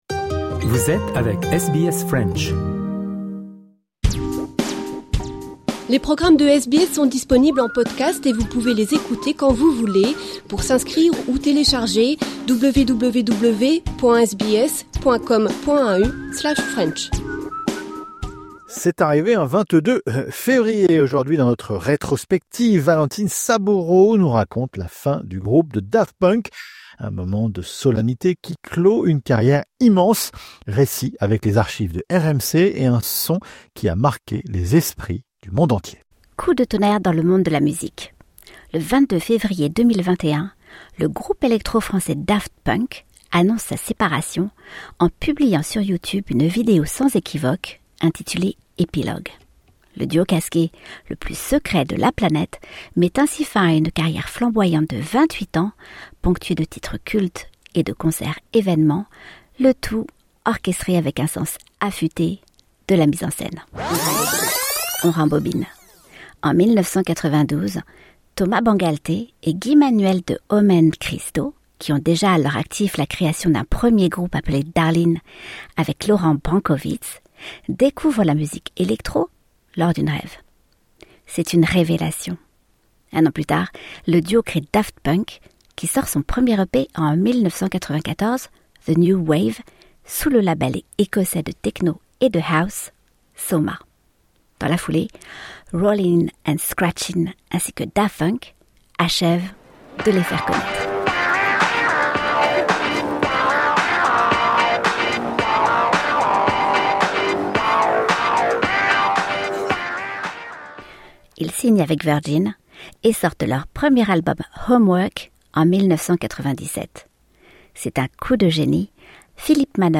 Un moment de solennité qui clôt une carrière immense. Récit, avec les archives de RMC et un son qui a marqué les esprits du monde entier.